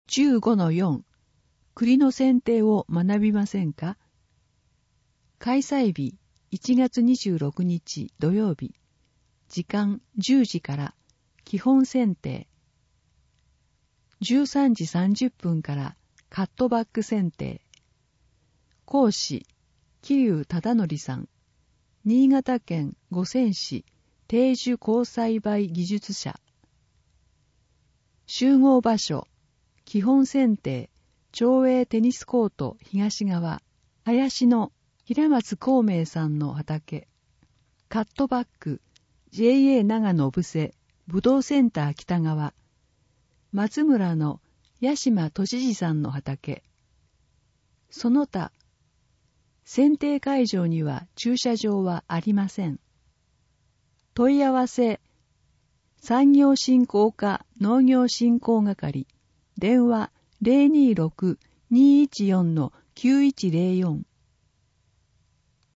毎月発行している小布施町の広報紙「町報おぶせ」の記事を、音声でお伝えする（音訳）サービスを行っています。 音訳は、ボランティアグループ そよ風の会の皆さんです。